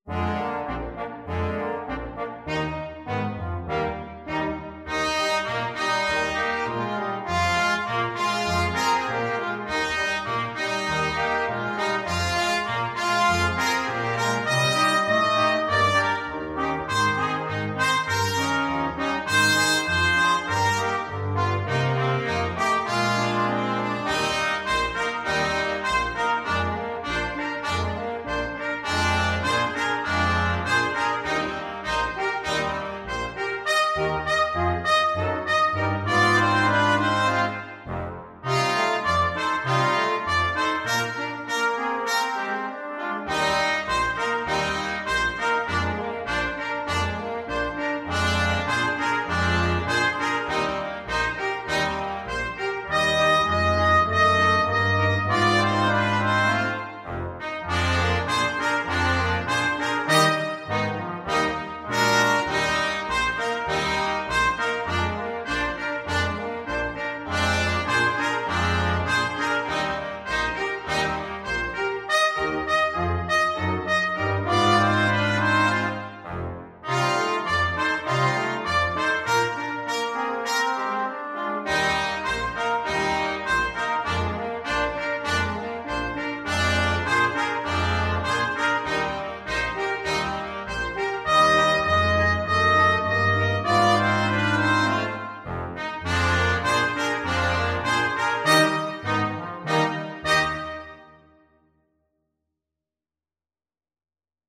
Trumpet 1Trumpet 2French HornTromboneTuba
2/2 (View more 2/2 Music)
Moderato =c.100
Pop (View more Pop Brass Quintet Music)